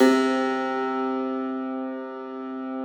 53k-pno06-C1.wav